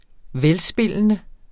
Udtale [ -ˌsbelˀənə ]